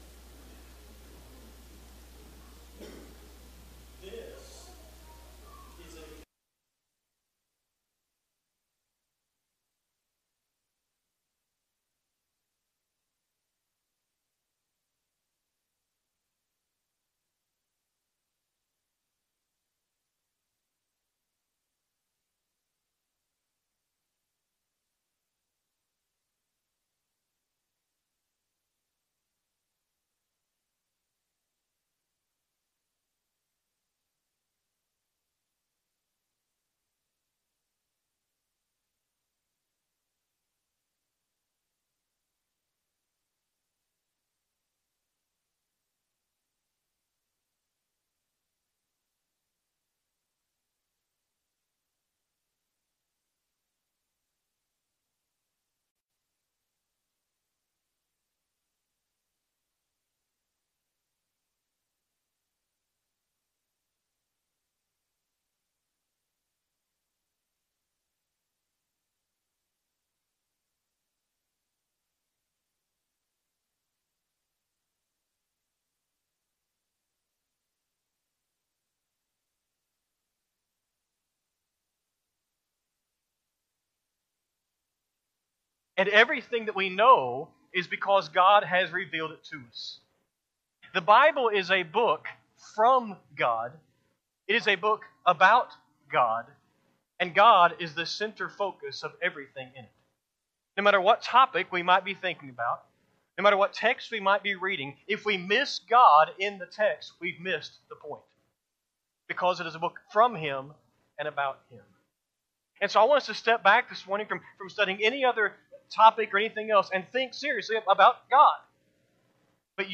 Sunday AM Sermon
Sunday-AM-Sermon-11-9-25-Audio.mp3